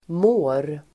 Uttal: [må:r]